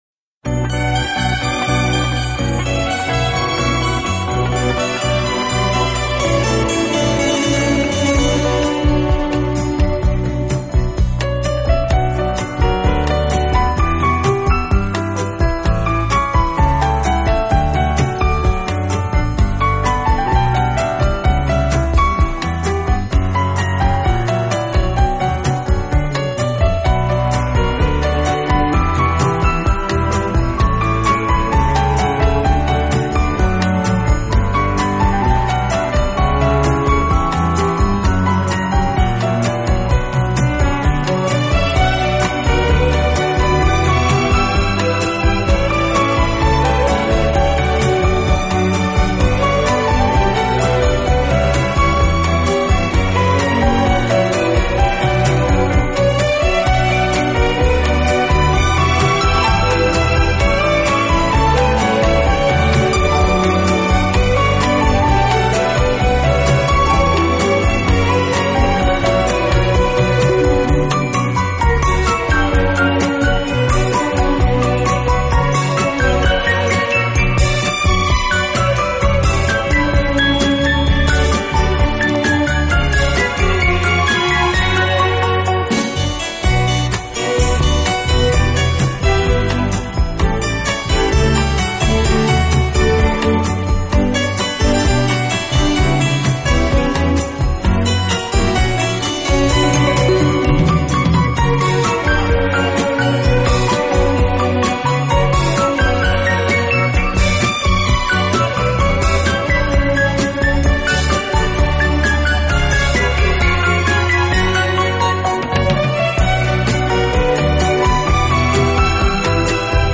音乐类型：Instrumental, Easy Listening